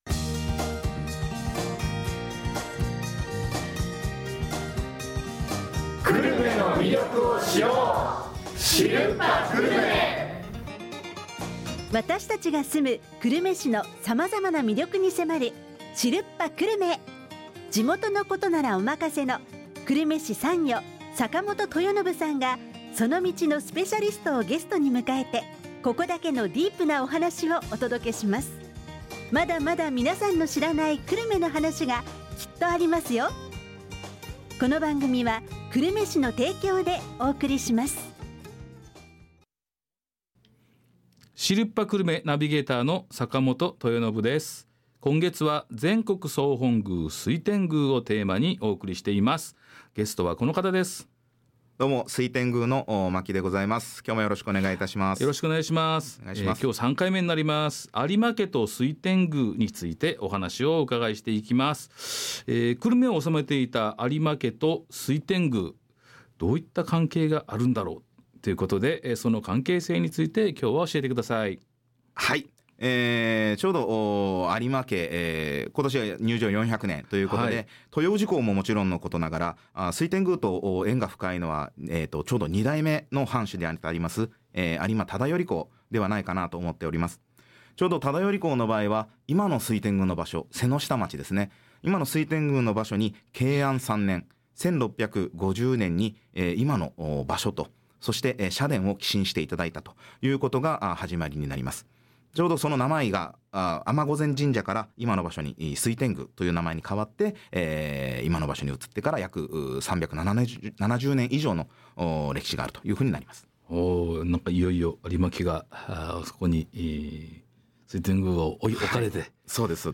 ドリームスFM「知るっぱ久留米」（76.5MHz） 毎週木曜日の、12時30分～12時40分に放送！